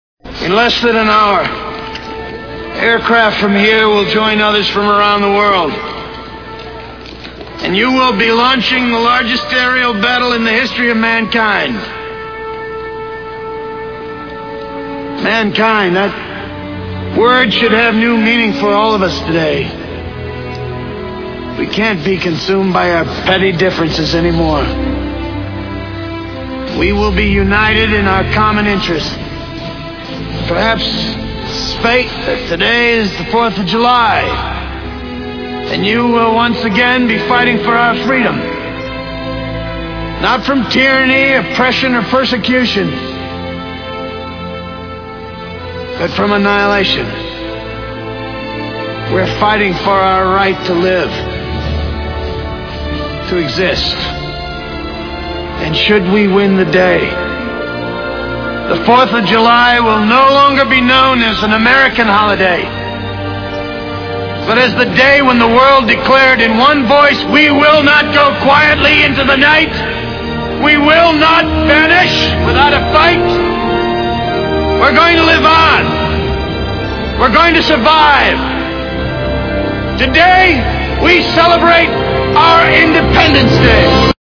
Listen to the President and fill in the missing words.